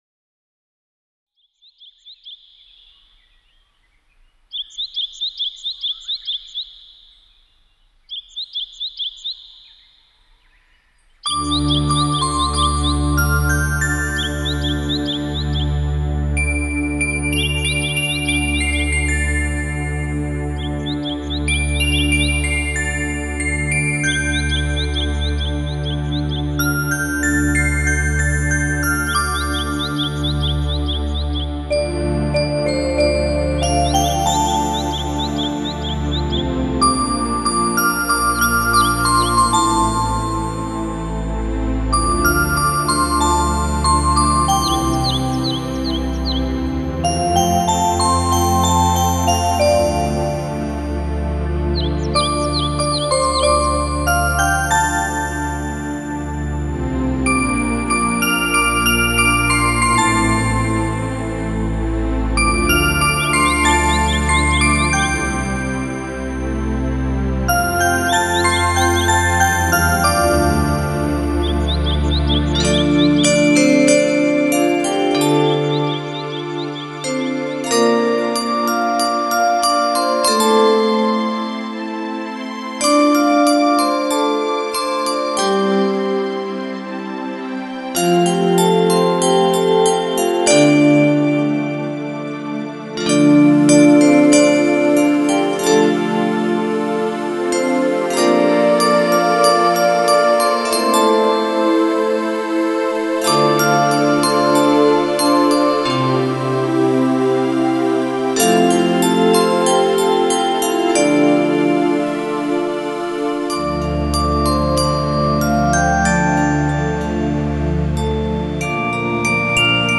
放进水晶般的音符里，沉浸、涤荡、漂净…
梵音的澄澈、轻扬，让心的忧容褪色、雾化，
清凉剔透的水晶，返璞归真的天籁，